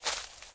StepForest2.ogg